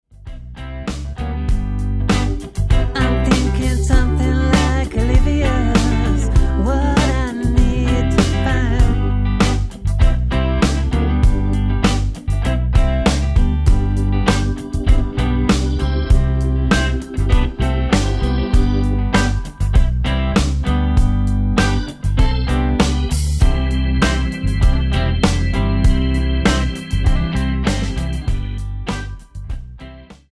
--> MP3 Demo abspielen...
Tonart:G mit Chor